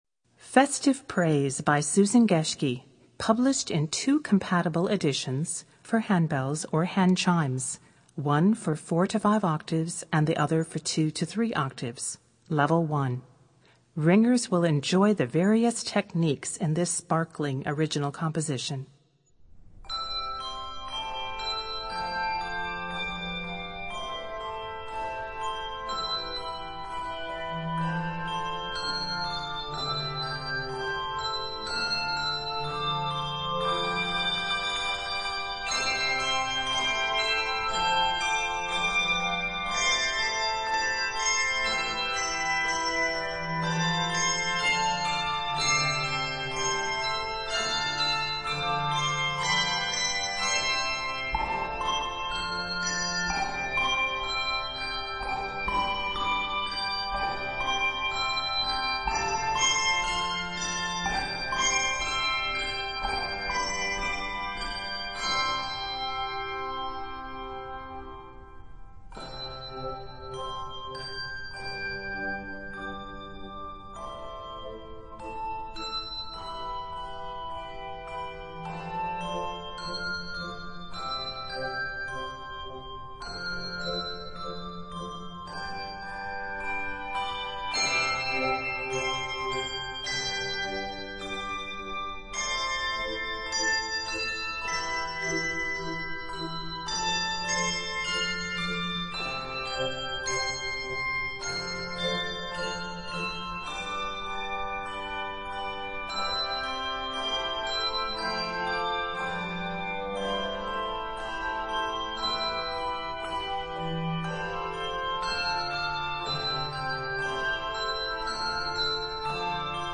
Voicing: Handbells 4-5 Octave